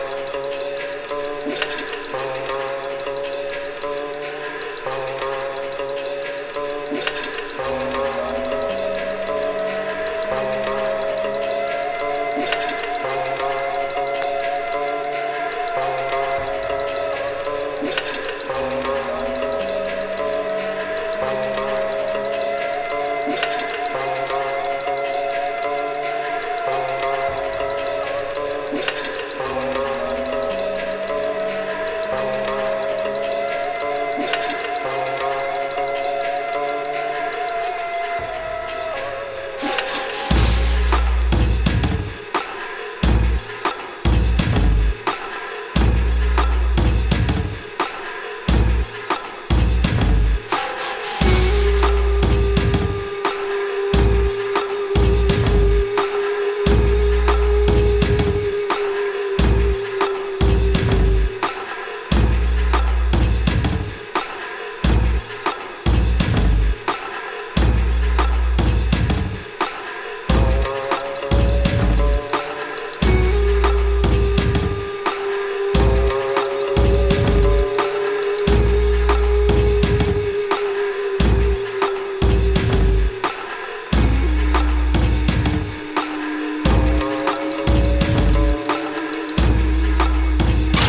北海道のアーティスト達によるインストトラック集
オリジナルなドープインスト収録